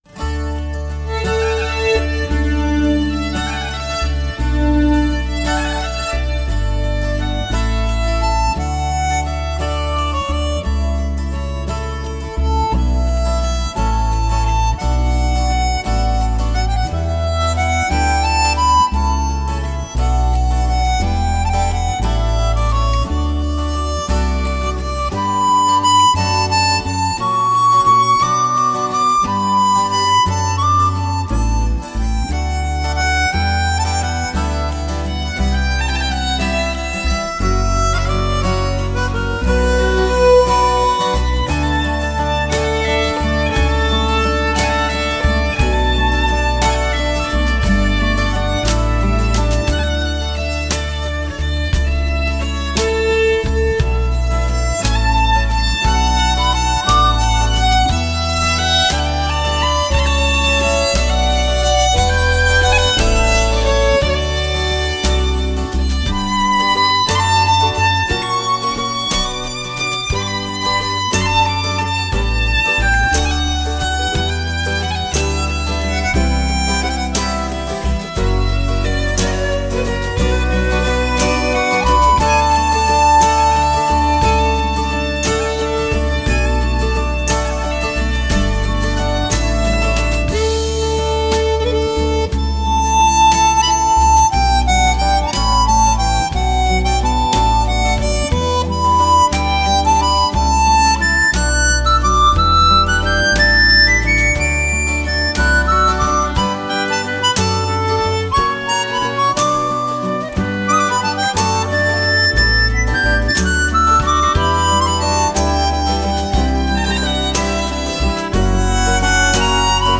口琴演奏